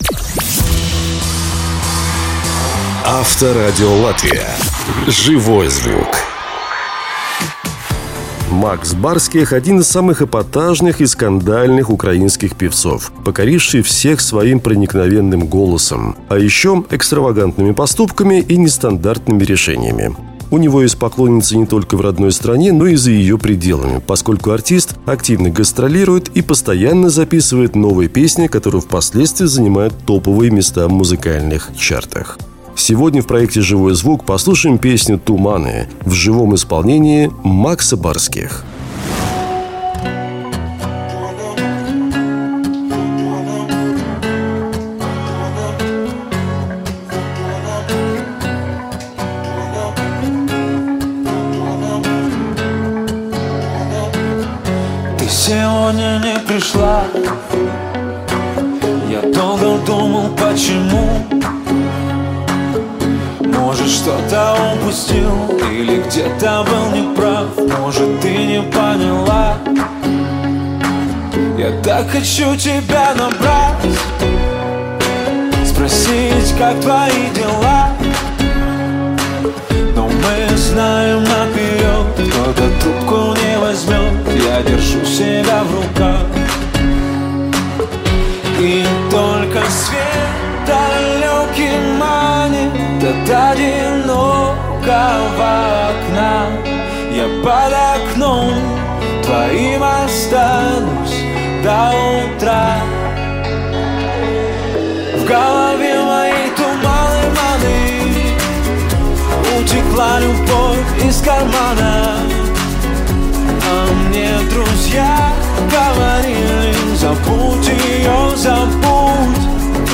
в живом исполнении звёзд!